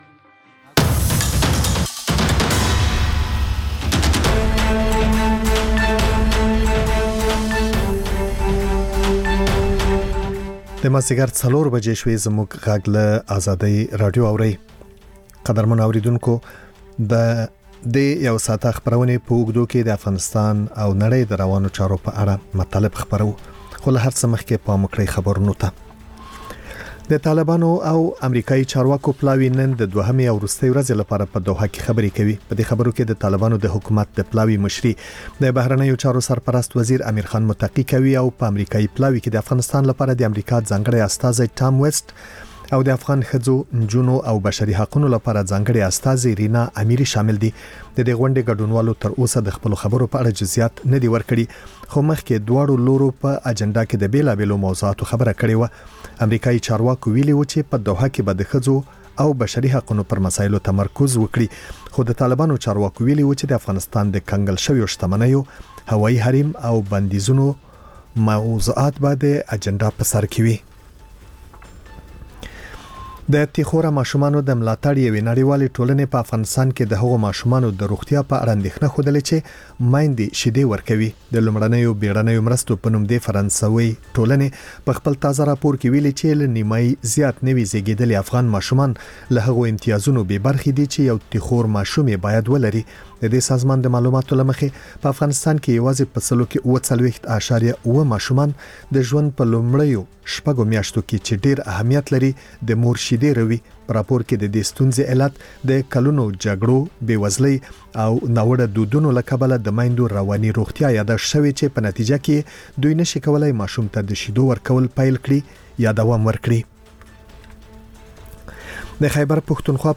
مازیګرنی خبري ساعت - P1 سټوډیو